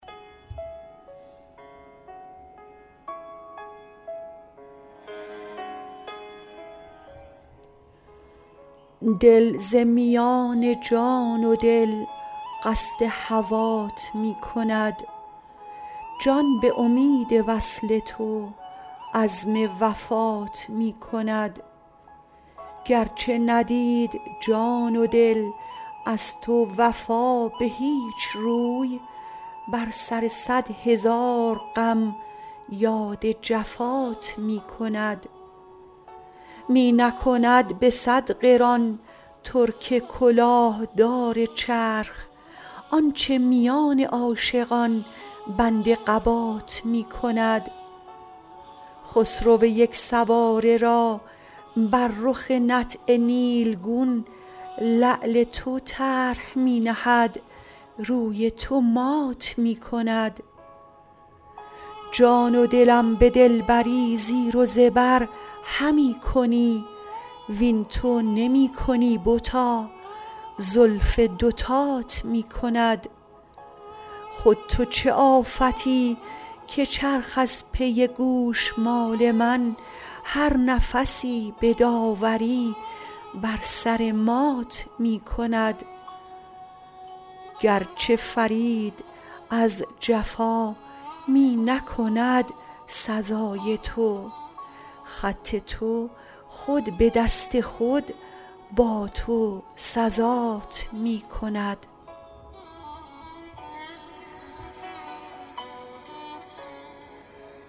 غزل شمارهٔ ۳۰۶ به خوانش